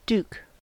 In most North American accents, /j/ is "dropped" or "deleted" in stressed syllables after all alveolar and dental consonants (that is: everywhere except after /p/, /b/, /f/, /h/, /k/, and /m/), so new, Tuesday, assume, duke are pronounced /nu/, /ˈtuzdeɪ/, /əˈsum/, /duk/
En-us-duke.ogg.mp3